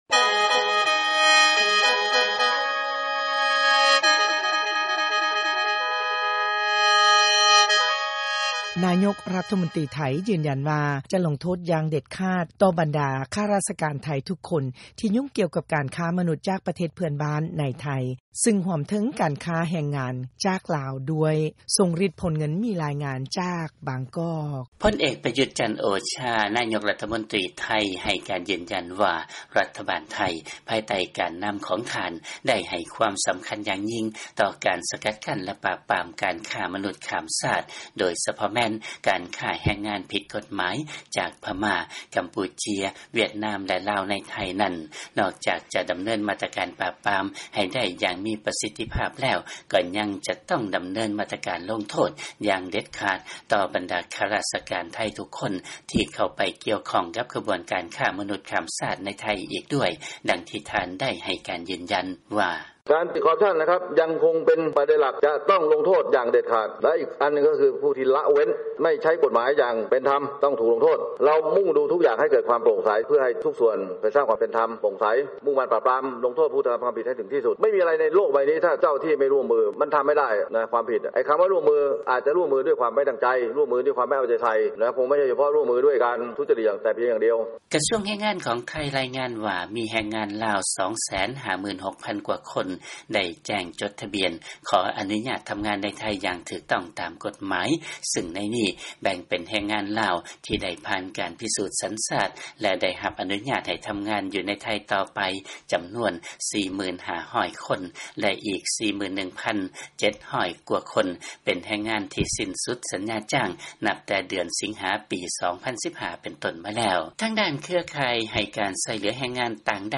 ເຊີນຟັງ ລາຍງານ ນາຍົກໄທ ຢືນຢັນວ່າ ຈະລົງໂທດ ຢ່າງເດັດຂາດ ຕໍ່ບັນດາຂ້າຣາຊະການໄທ ທຸກຄົນທີ່ຫຍຸ້ງກ່ຽວກັບການຄ້າມະນຸດ